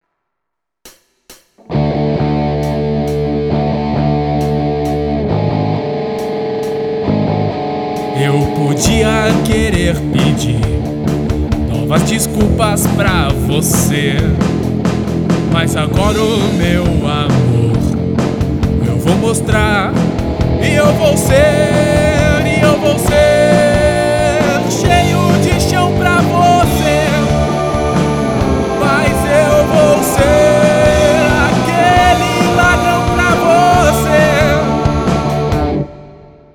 Guitarras e contrabaixo
Bateria